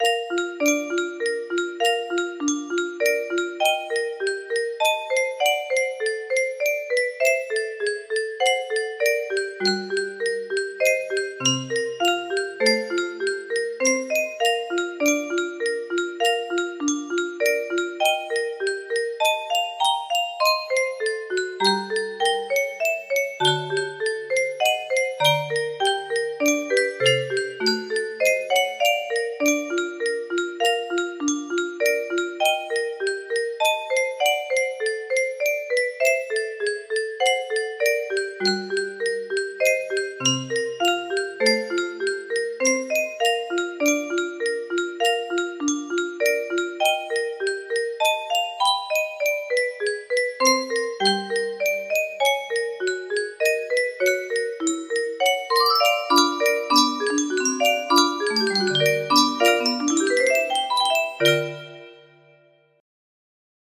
Lullaby for Mila music box melody